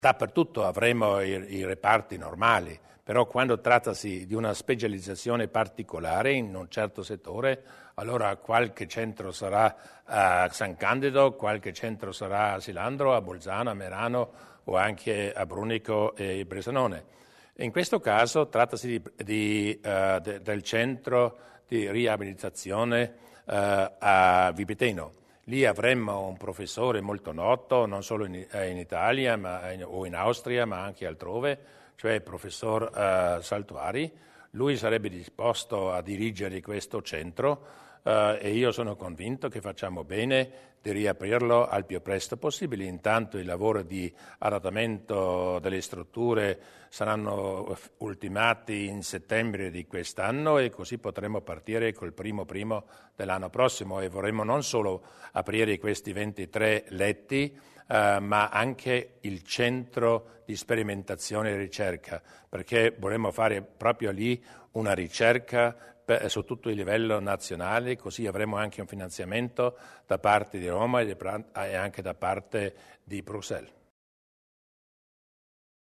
Il Presidente Durnwalder sul centro di neuroriabilitazione di Vipiteno